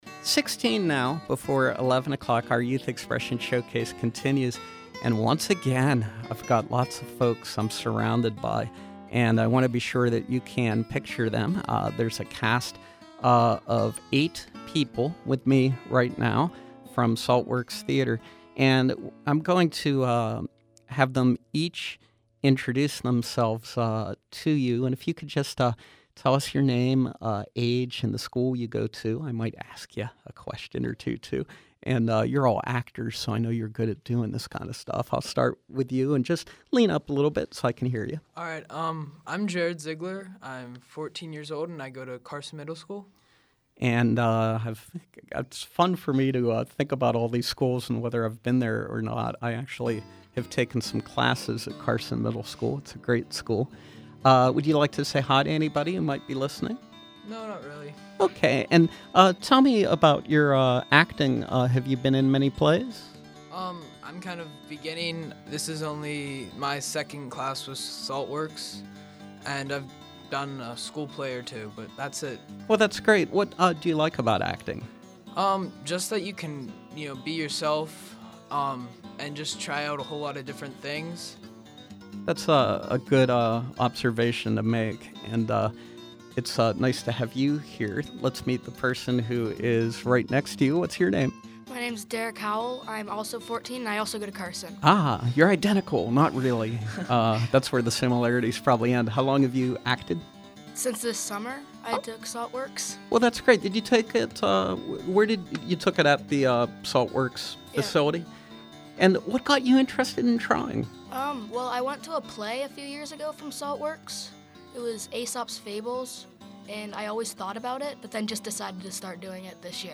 Joining us, members of the Young Actors Ensemble at Saltworks Theater – a group of teens aged 14-16 years old. Here they share with us excerpts from their upcoming production, The Complete History of America (Abridged).